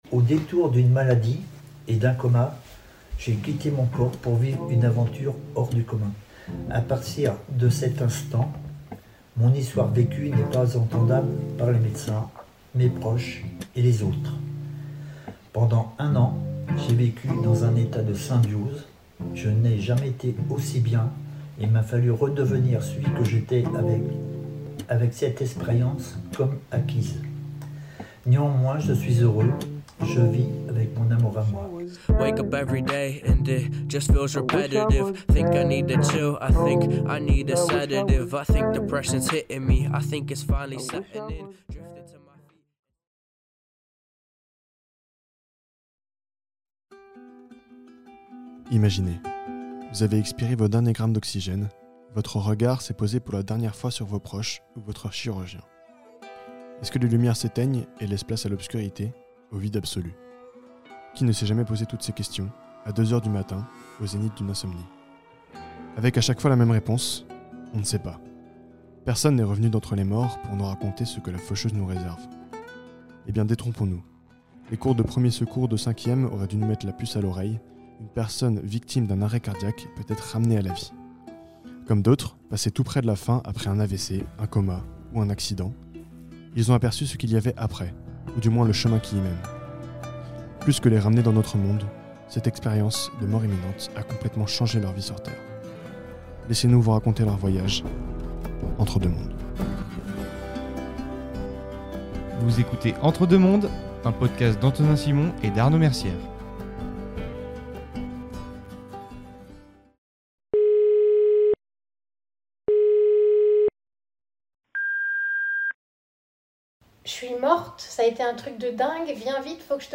Avec les interviews de